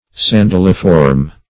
Search Result for " sandaliform" : The Collaborative International Dictionary of English v.0.48: Sandaliform \San*dal"i*form\, a. [Sandal + -form.]